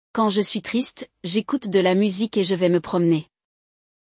No diálogo deste episódio, um dos personagens compartilha com um amigo o que faz para se animar quando está para baixo.